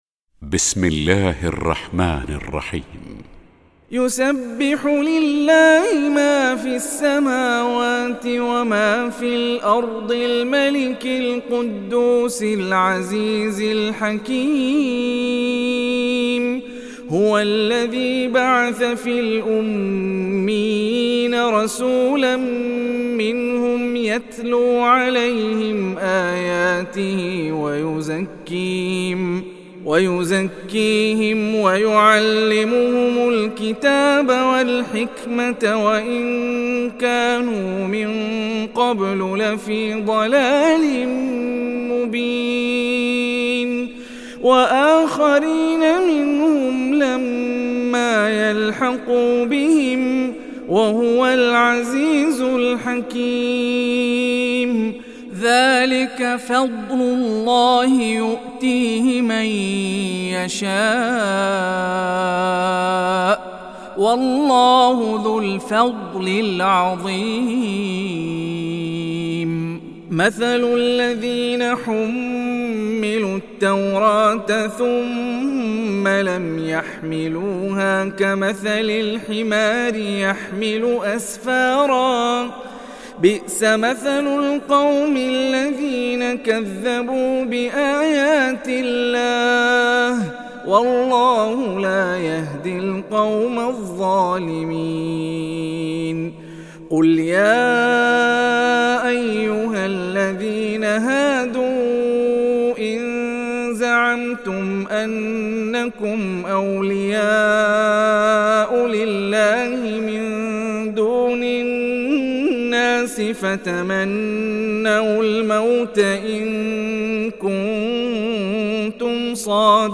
تحميل سورة الجمعة mp3 هاني الرفاعي (رواية حفص)